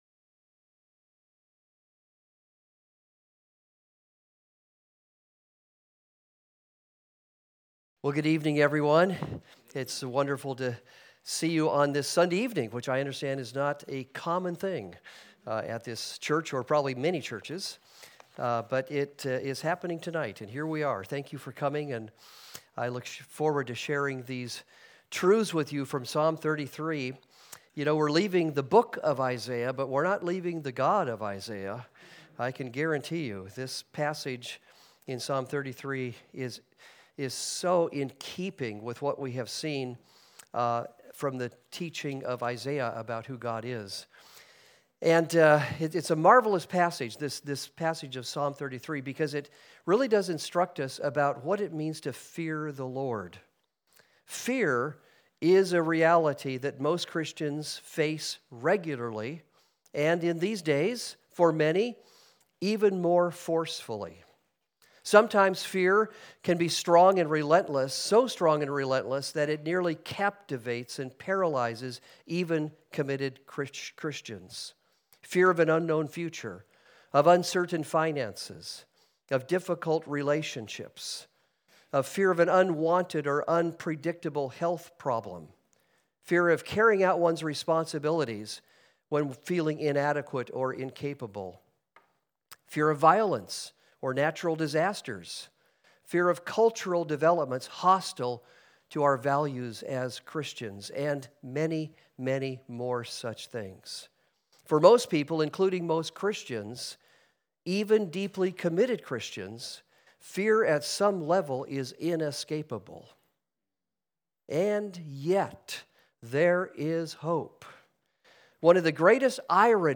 GOD IS - The Attributes of God Conference